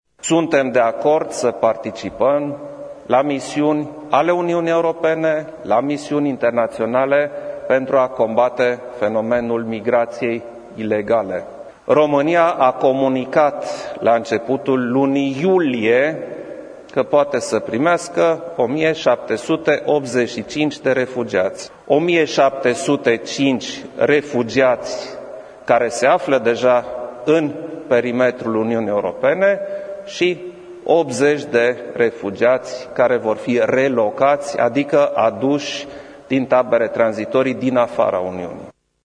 Declarația a fost făcută de președintele Klaus Iohannis în urmă cu puţin timp. Potrivit președintelui, este nevoie de solidaritate, dar este mai bine ca fiecare stat membru să stabilească singur câți refugiați poate să primească.